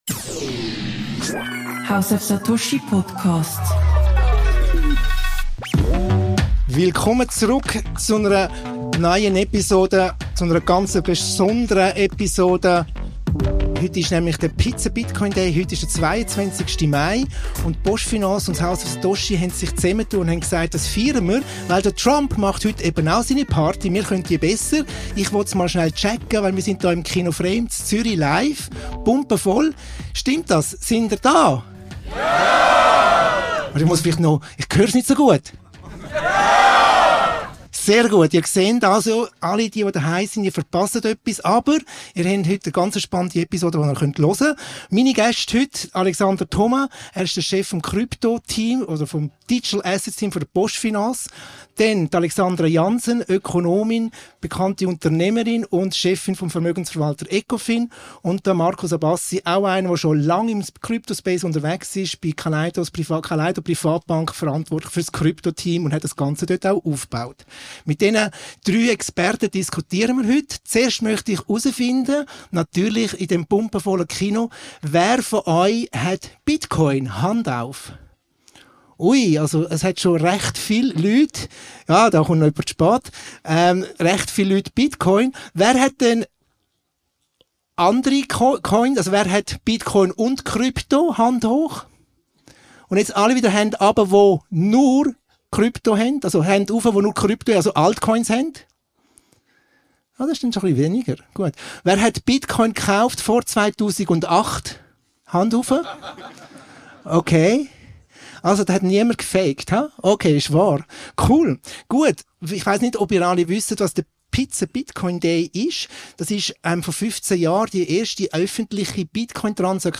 Spezialfolge: Live-Podcast zum Thema Krypto - Rückblick auf den Bitcoin Pizza Day Event in Zürich ~ POPCORN & FINANZEN Podcast
House of Satoshi und PostFinance spannen zusammen – und feiern gemeinsam den Bitcoin Pizza Day mit einem einzigartigen Live-Podcast im Kino Frame.
- Was müssen Privatanleger verstehen, bevor sie investieren – welche Chancen gibt es, welche Fallen lauern, und wie lässt sich Krypto überhaupt sinnvoll umsetzen? Diese Experten diskutieren – ungefiltert, ungeschnitten